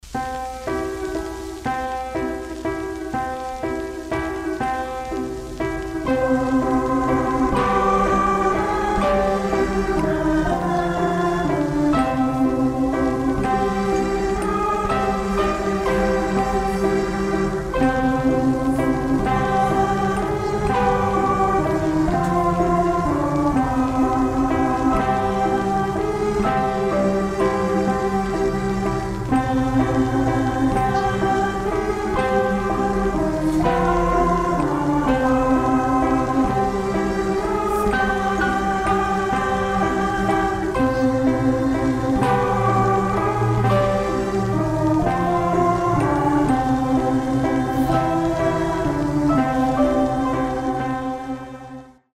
инструментальные , пугающие , жуткие , страшные